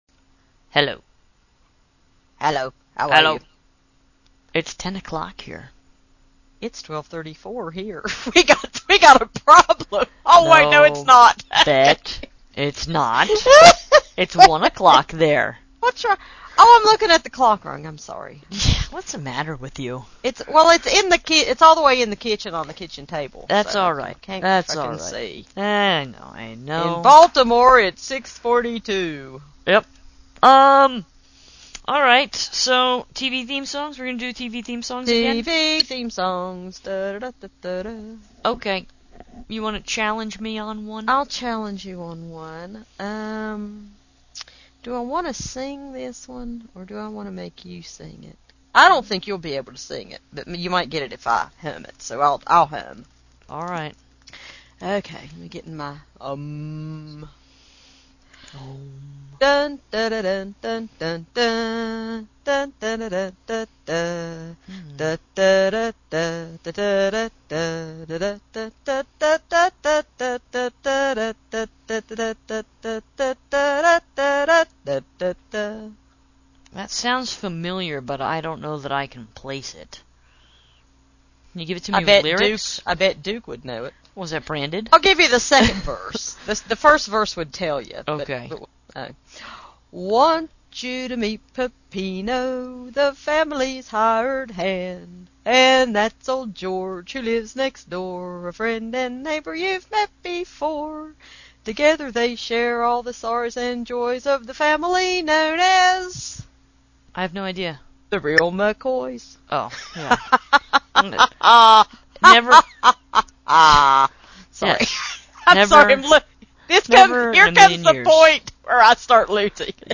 I’m glad you went back to pick up the first verse of Charles in Charge.